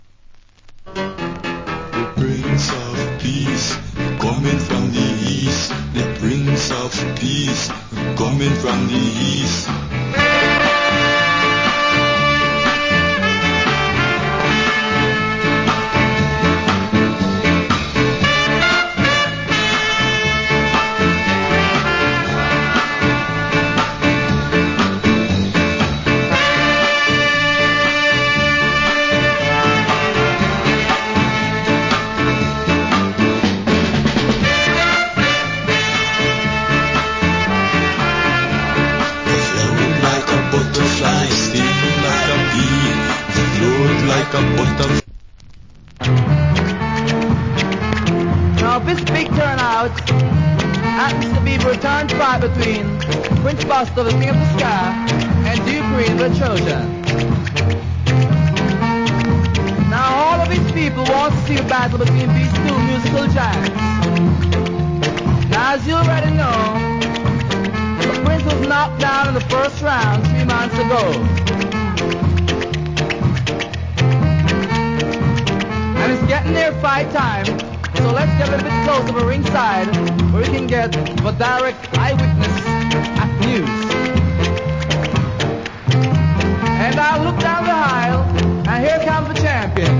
Killer Ska Inst.